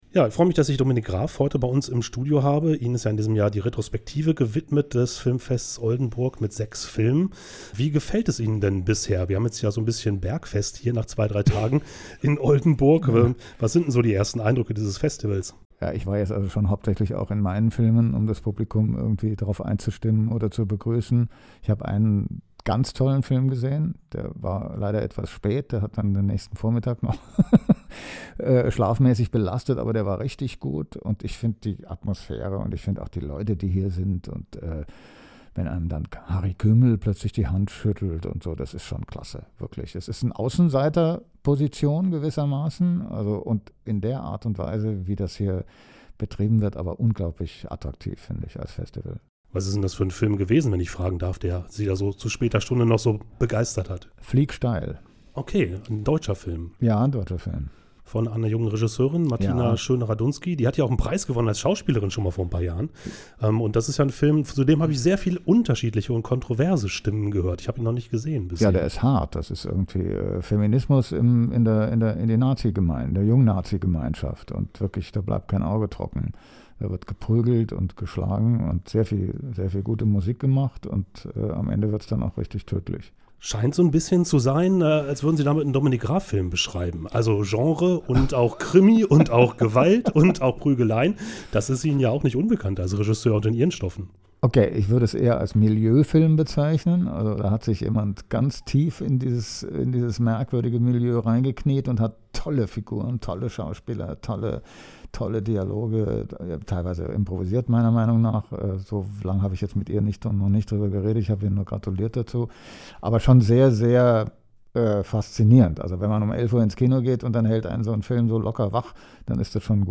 Radiointerview mit Dominik Graf im Studio von Oldenburg Eins – 14.09.2024